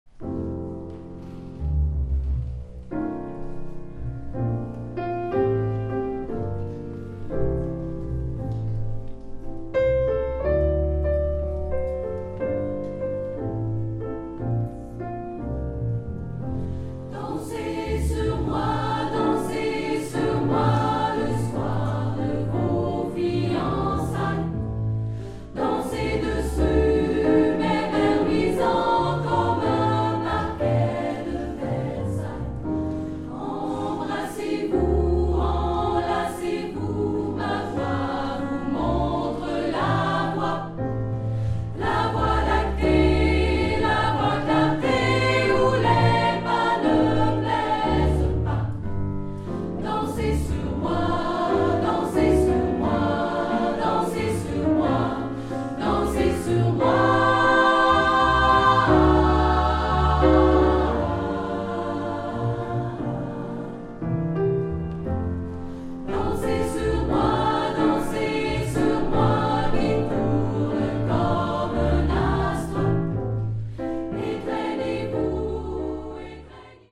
chorale Jazz
la chorale est accompagnée de musiciens fantastiques